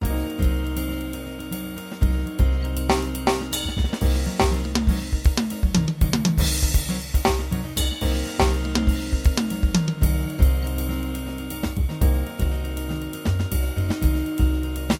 backing tracks
Locrian Mode